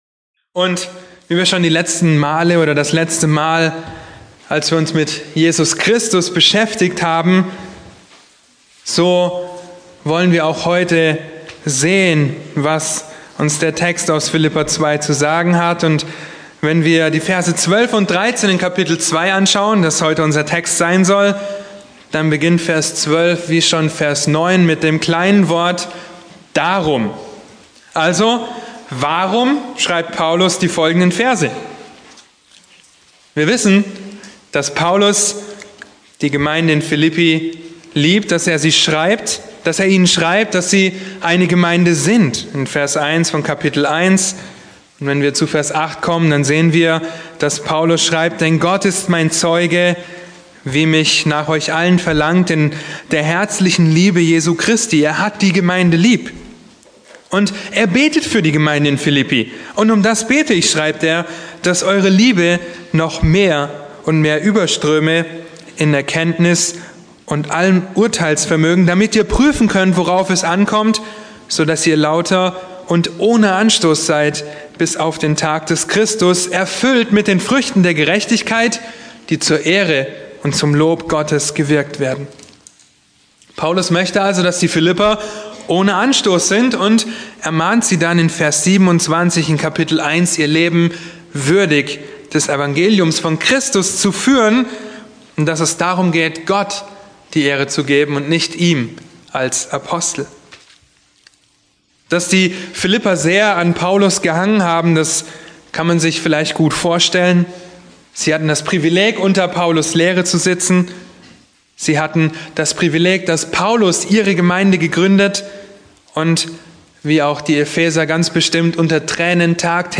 Eine predigt aus der serie "Weisheit Praktisch*." Epheser 5,15